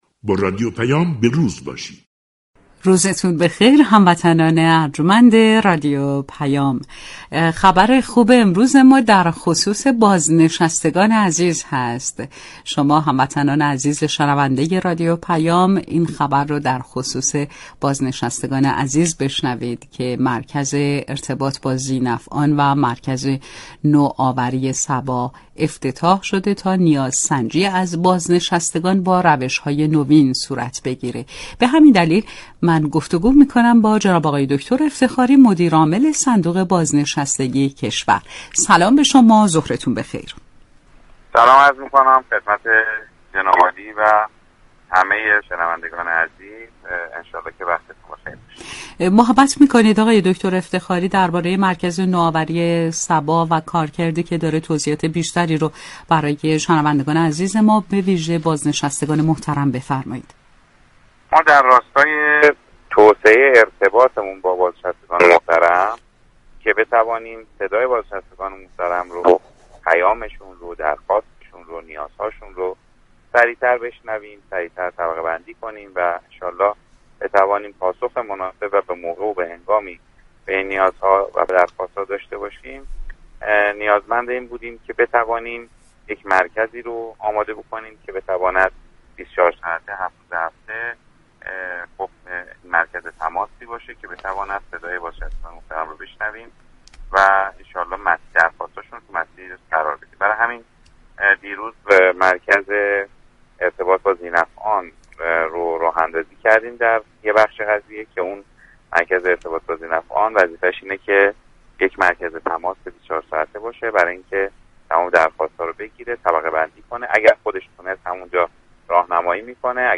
دكتر افتخاری مدیر عامل صندوق بازنشستگی كشور در گفتگو با رادیو پیام ، جزئیات مركز ارتباط با ذینفعان و نوآوری صبا بازگو كرد .